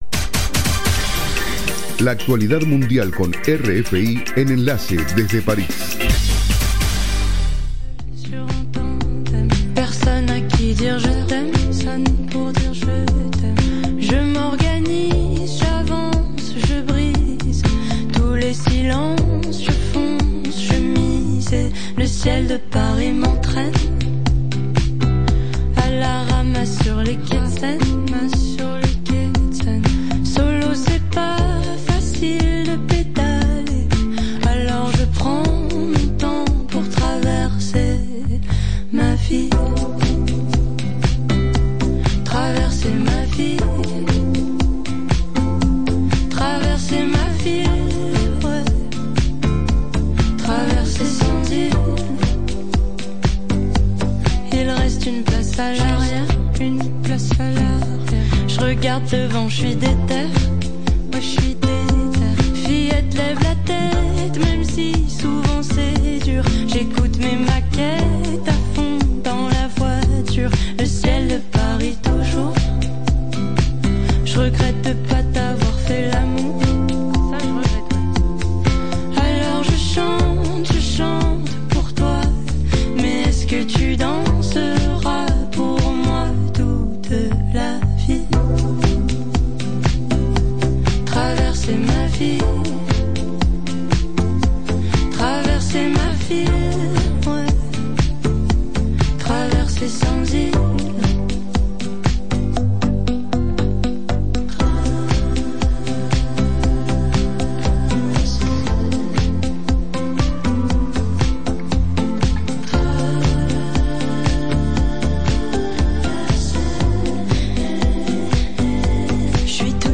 Programa: RFI - Noticiero de las 07:00 Hs.